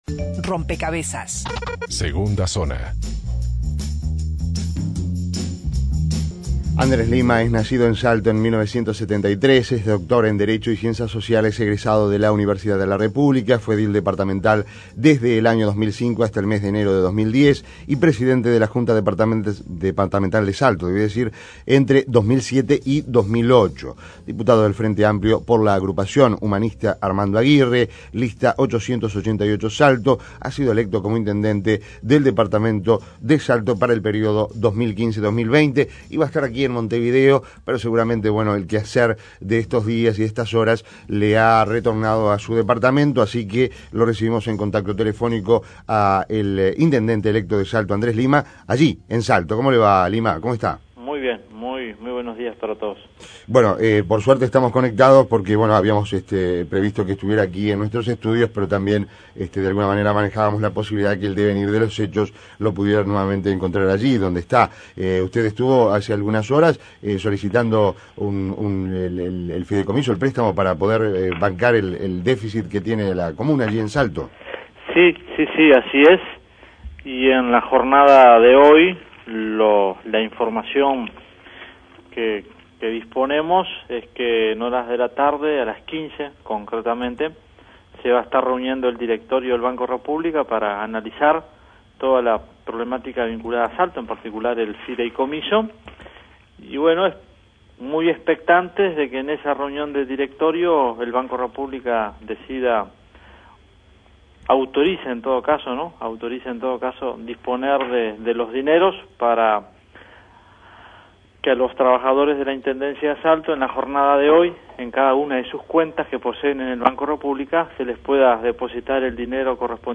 El intendente electo de Salto, Andrés Lima (FA), dijo en diálogo con Rompkbzas que el Banco República (BROU) le informó que hoy, a partir de la hora 15, se depositará el sueldo adeudado del mes de mayo y el medio aguinaldo de junio a los casi 2 mil funcionarios municipales, por lo que espera que entre hoy y mañana levanten la paralización que mantienen desde hace 10 días.